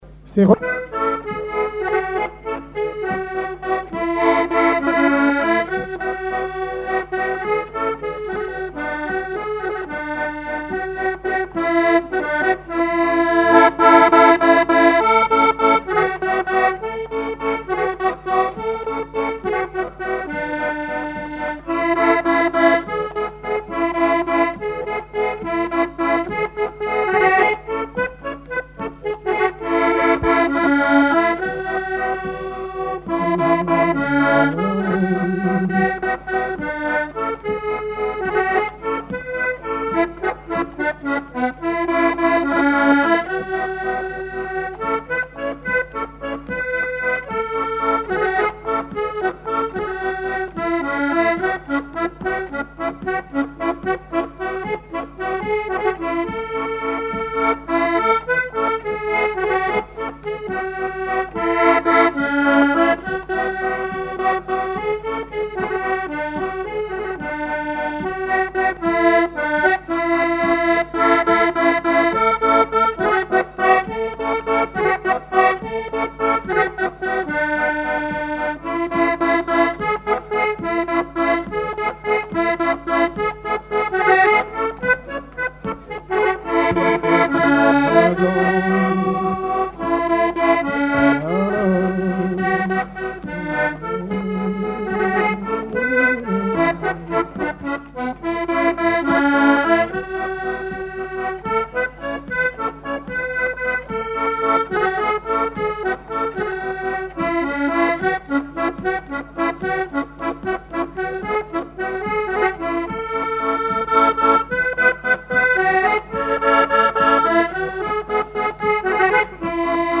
danse : valse
collectif de musiciens pour une animation à Sigournais
Pièce musicale inédite